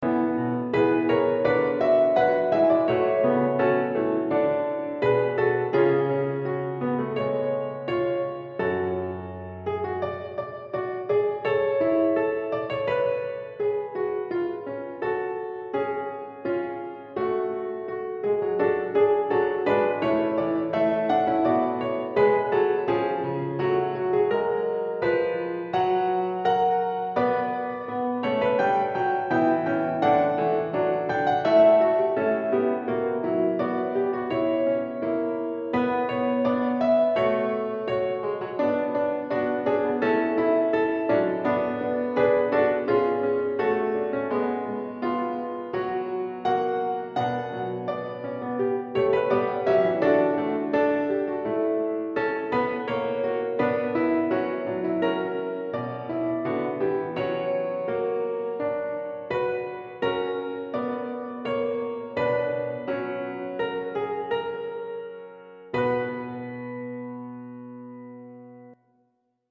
Accompaniment
domine_accompaniment.mp3